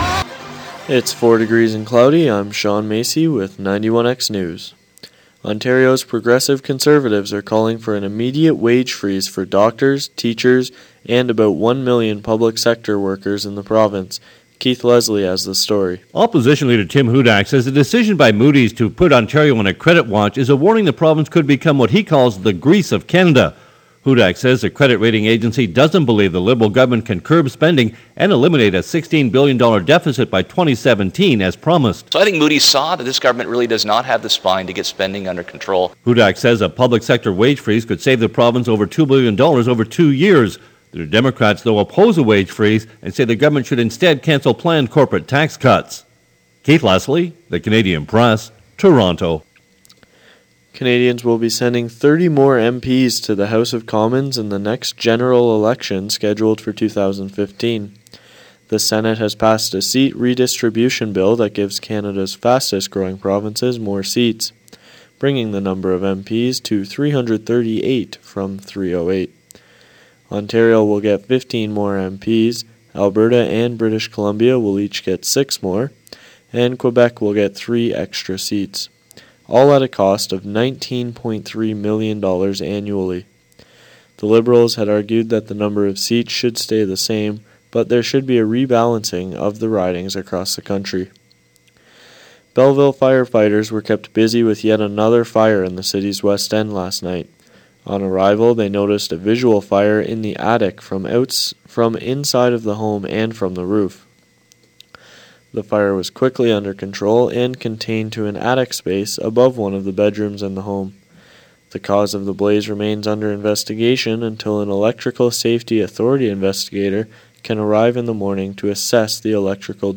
Ontario’s progressive conservatives are calling for wage freeze for doctors in the province. Belleville firefighters respond to a fire in the attic of a west end home and the autopsy was conducted today of a murder suicide of four young people in Calgary. For these stories, sports and more listen the 1pm newscast.